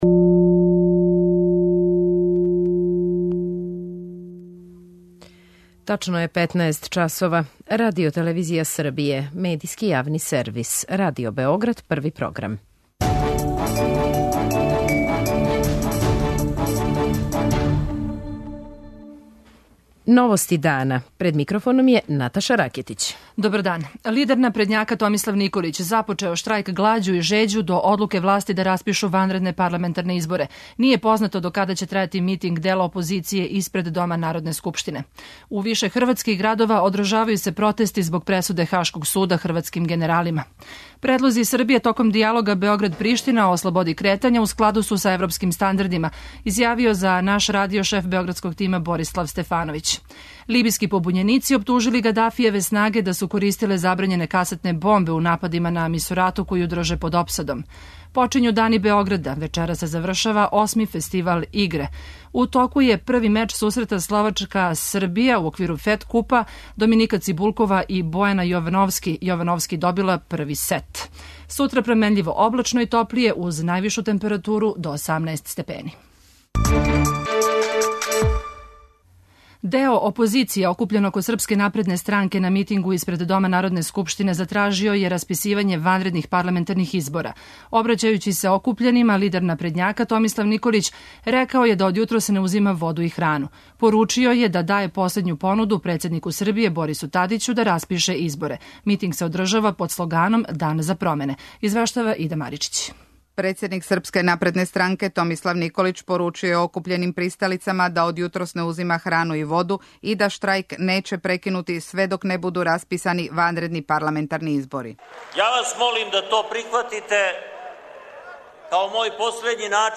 Наши репортери прате протест опозиције окупљене око Српске напредне странке испред Дома народне скупштине. Лидер СНС-а Томислав Николић рекао је да је ступио у штрајк глађу који неће прекинути док не буду расписани избори.